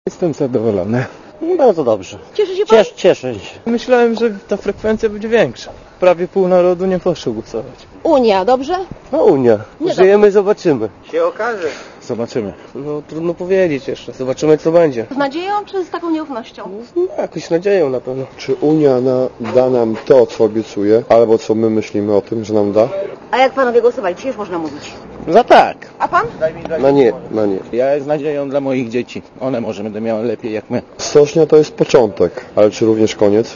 Stoczniowcy